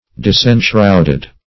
Search Result for " disenshrouded" : The Collaborative International Dictionary of English v.0.48: Disenshrouded \Dis`en*shroud"ed\, a. Freed from a shroudlike covering; unveiled.
disenshrouded.mp3